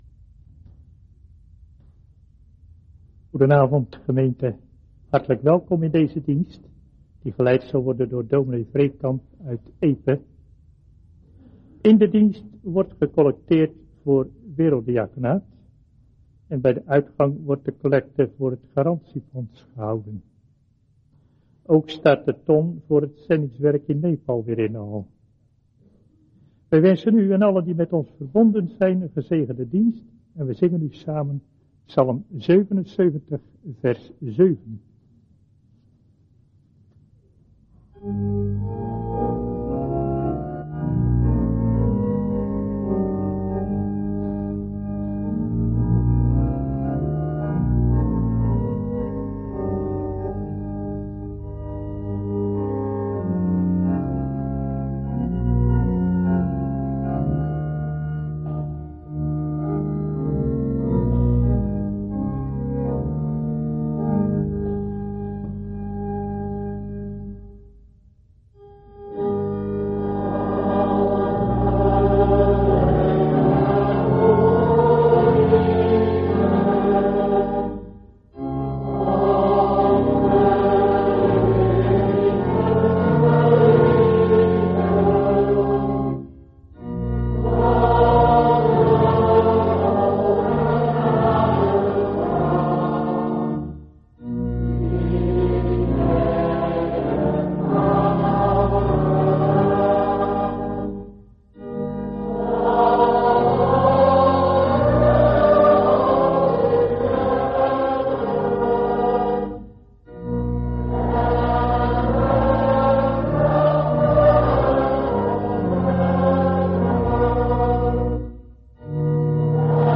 Dienst beluisteren Orde van de dienst